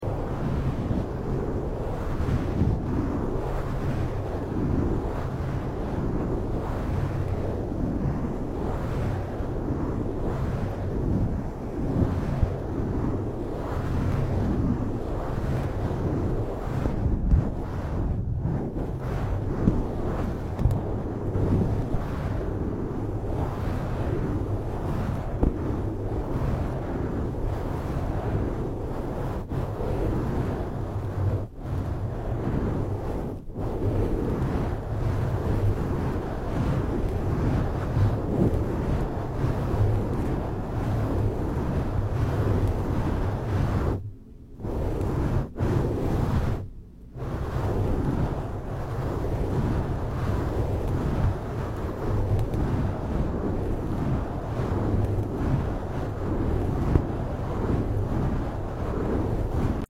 Windscreen tingles to help you sound effects free download
Windscreen tingles to help you get some well feserved relaxation.